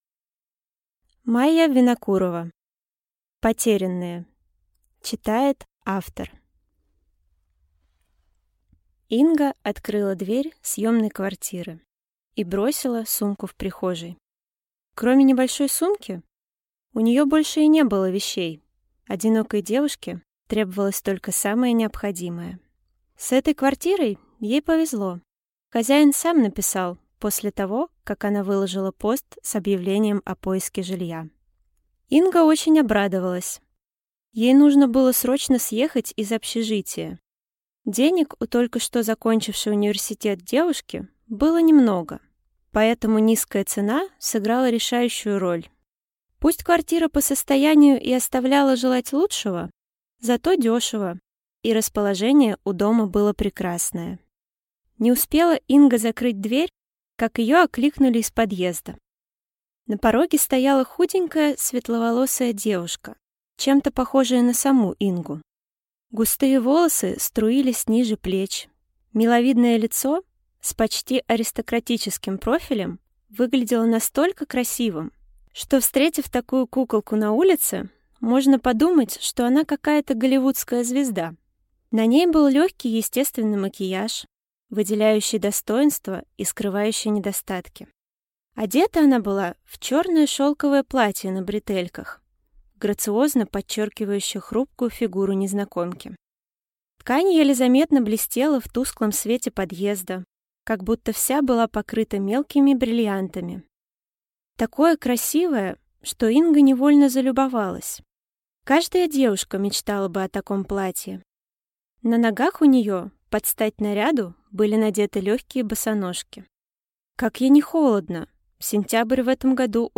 Аудиокнига Потерянные | Библиотека аудиокниг